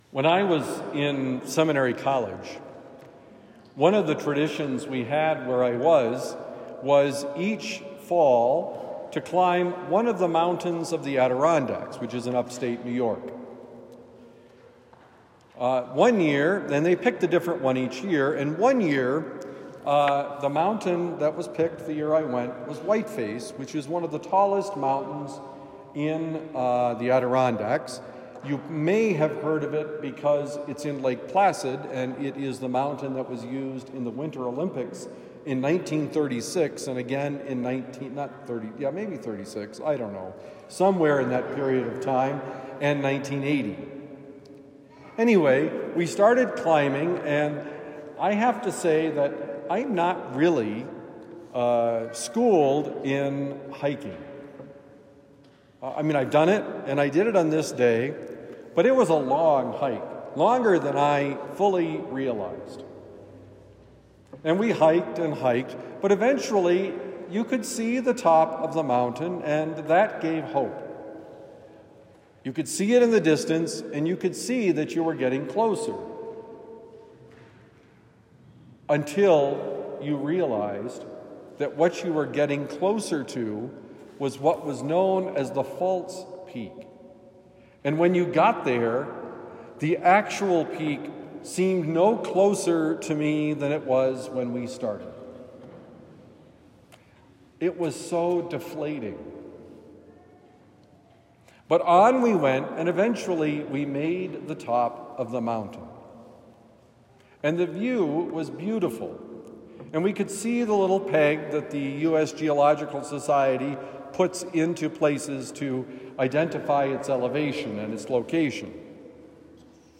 Let God Prepare the Way: Homily for Sunday, December 8, 2024